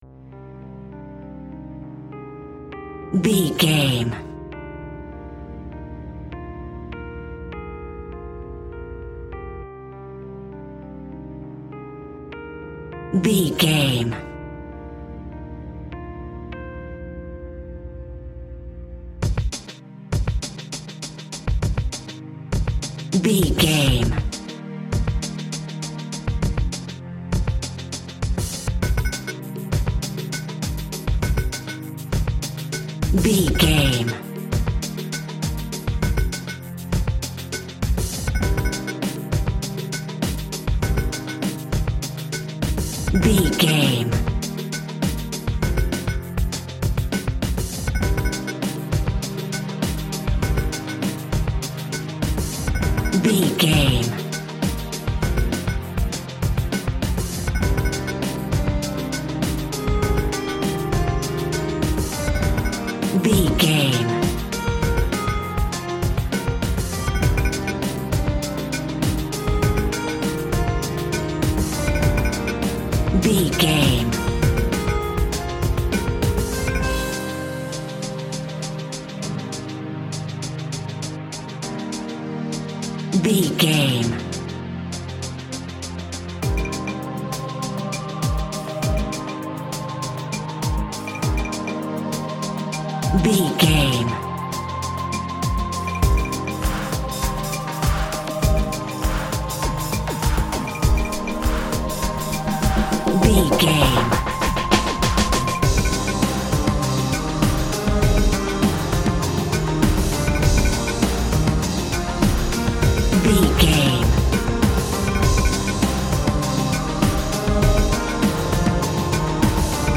Reality TV Broken Romance Music.
Aeolian/Minor
driving
uplifting
hypnotic
industrial
drums
electric piano
synthesiser
bass guitar
electric guitar